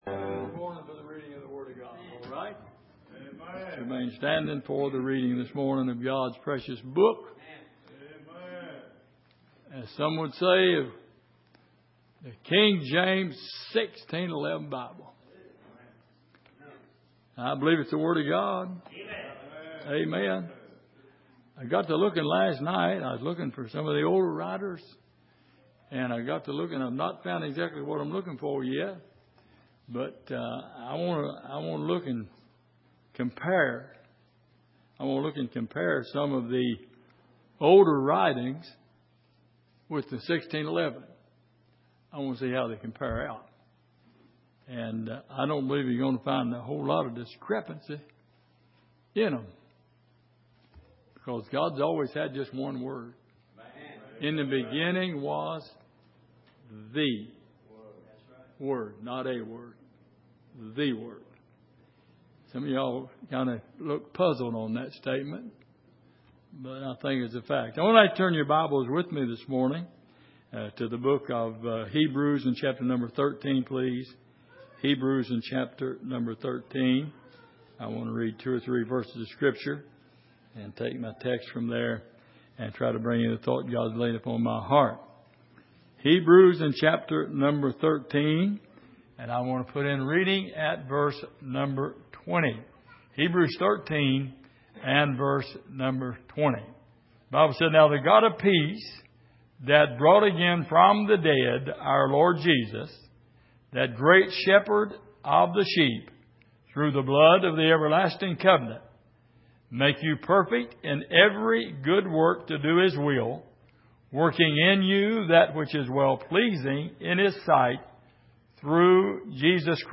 Passage: Hebrews 13:20-21 Service: Sunday Morning